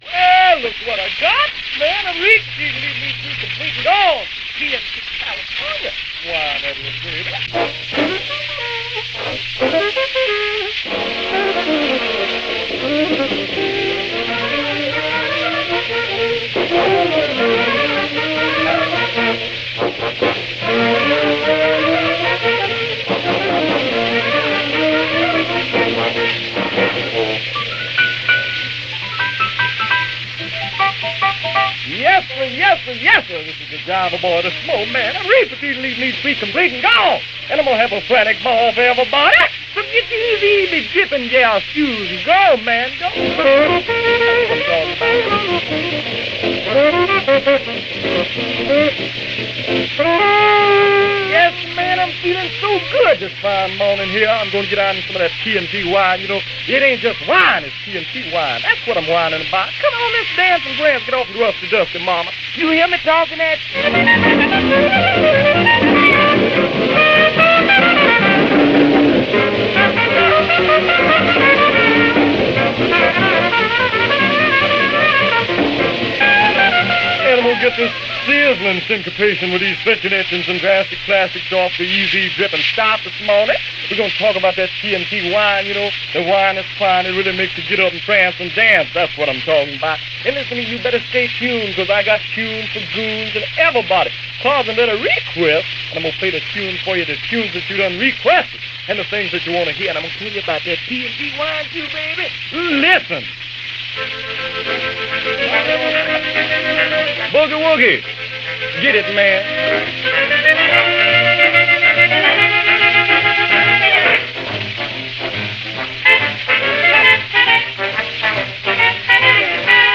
Sadly, the technical quality of this broadcast recording is very poor – not well recorded at the time – well worn from most likely too many playings, but an important document from a period of time when Segregation was an American way of life with the first glimpses of changing all that began around 1947 by way of the Military.